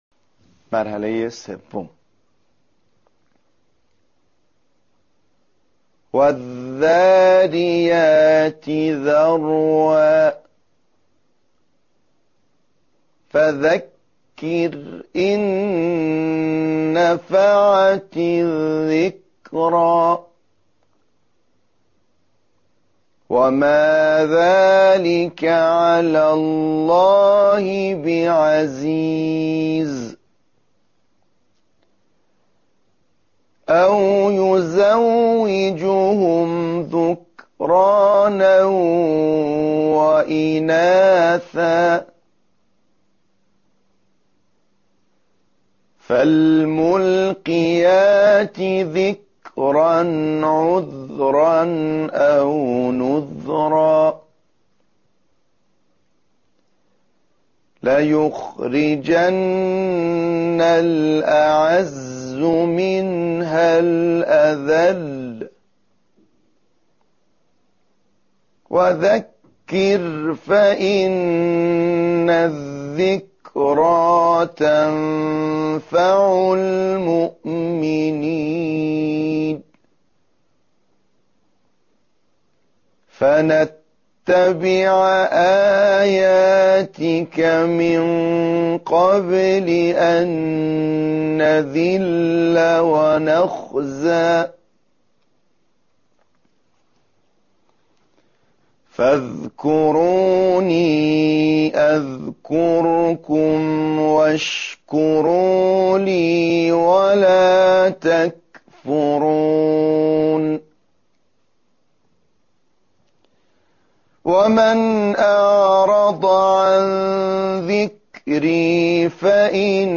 🔸ابتدا به تلفظ حرف «ذ» در این کلمات گوش فرا دهید و سپس آنها را تکرار کنید.